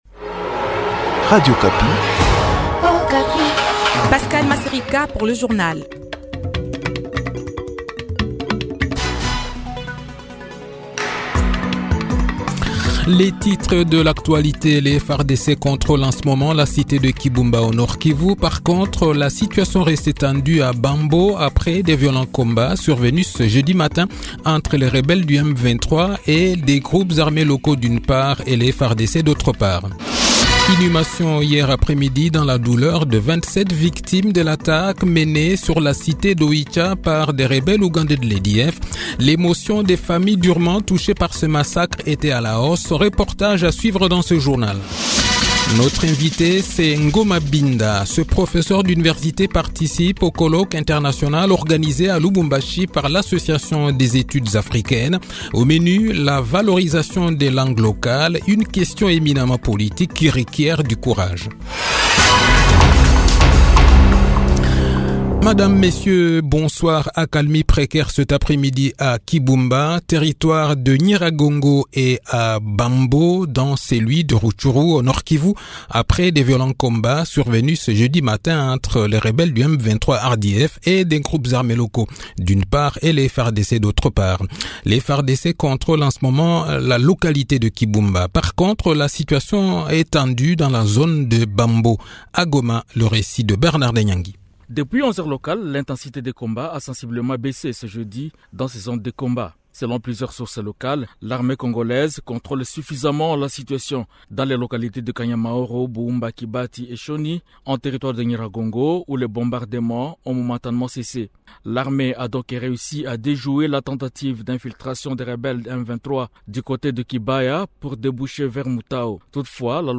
Le journal de 18 h, 26 octobre 2023